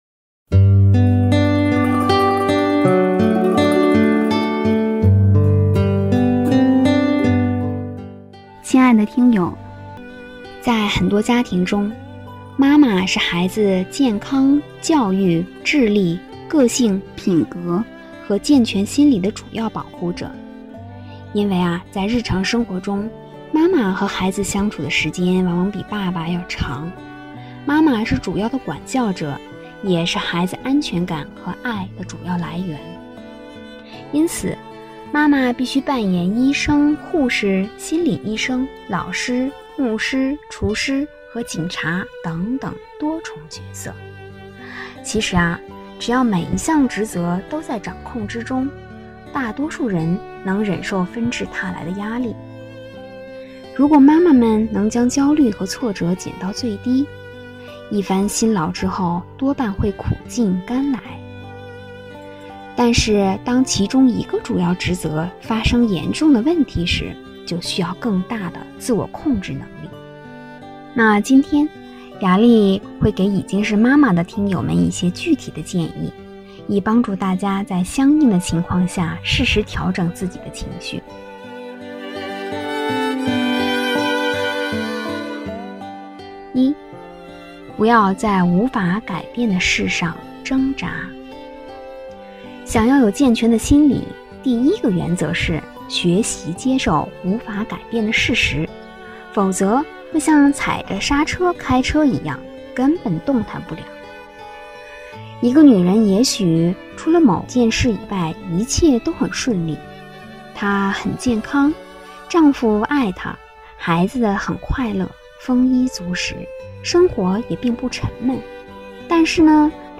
首页 > 有声书 > 婚姻家庭 > 单篇集锦 | 婚姻家庭 | 有声书 > 给妈妈们的贴心话